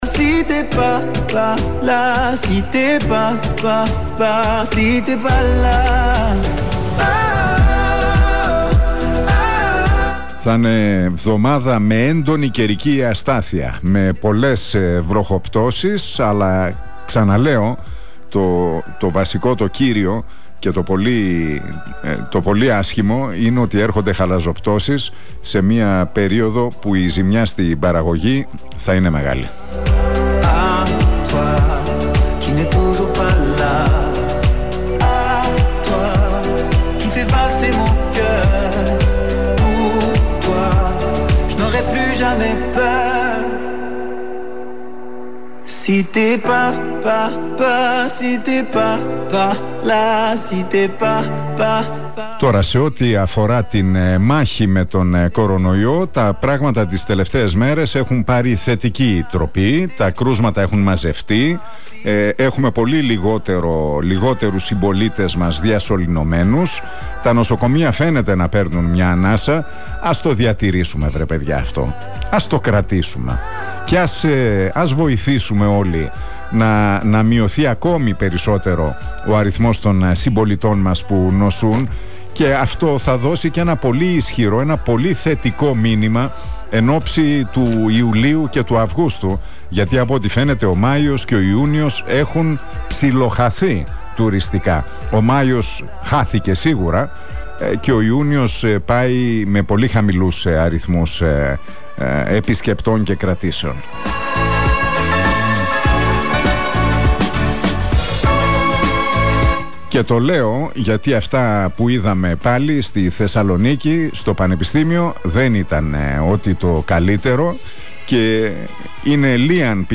Ακούστε το σημερινό σχόλιο του Νίκου Χατζηνικολάου στον Realfm 97,8...